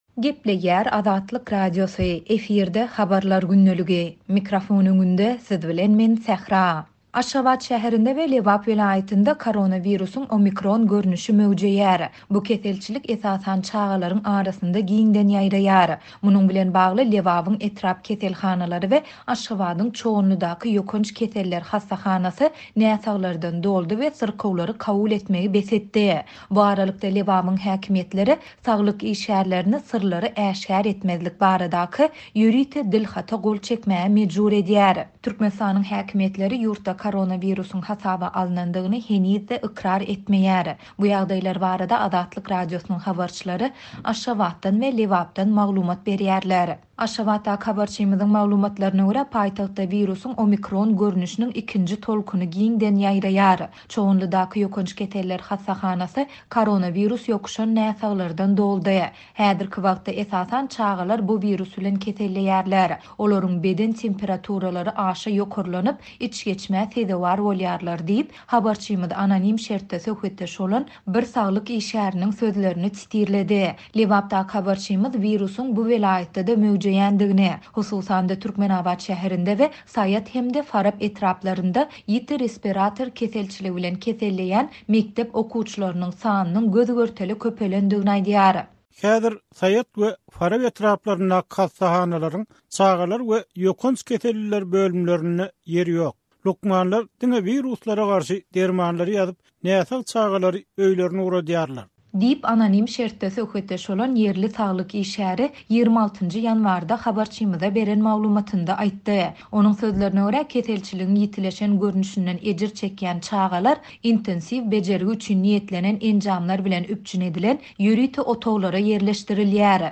Bu ýagdaýlar barada Azatlyk Radiosynyň habarçylary Aşgabatdan we Lebapdan maglumat berýärler.